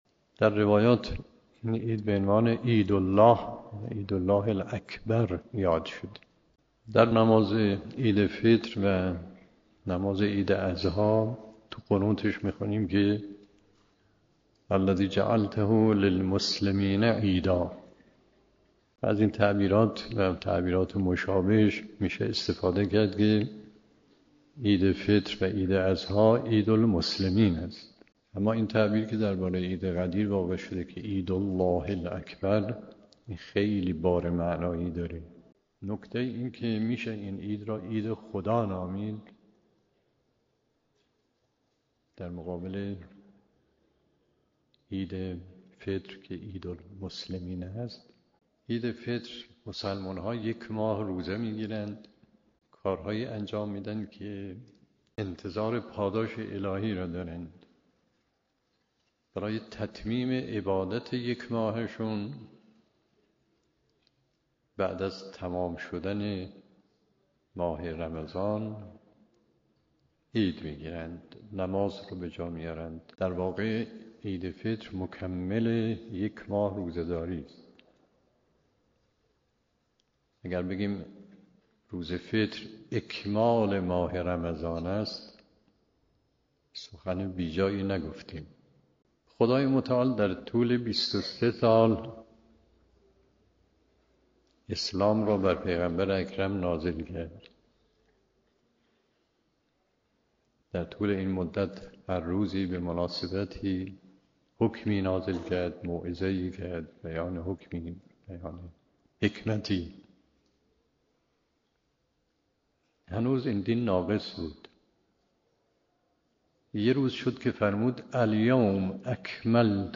به گزارش خبرگزاری حوزه، مرحوم علامه مصباح در یکی از سخنرانی‌ها به موضوع «چرا غدیر بزرگترین عید خداست» اشاره کردند که تقدیم شما فرهیختگان می‌شود.